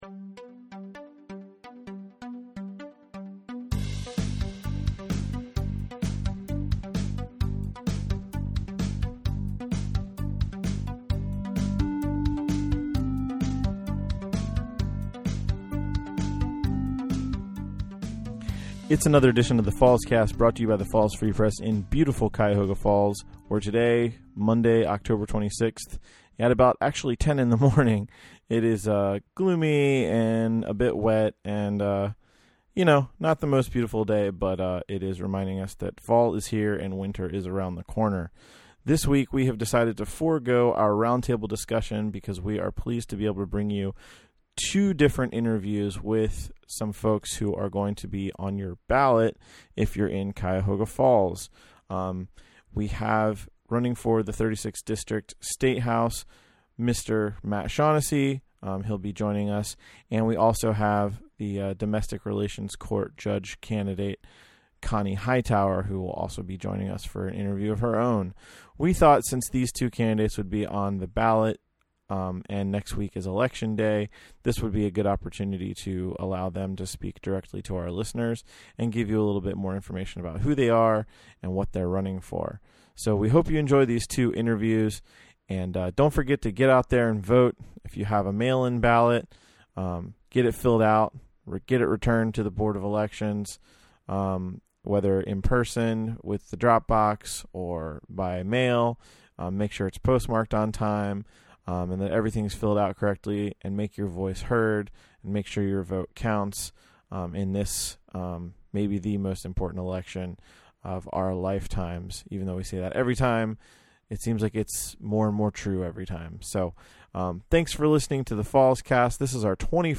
This week we have decided to forgo our roundtable discussion because we are please to be able to bring you two different interviews with folks who are going to be on your ballot on November 3.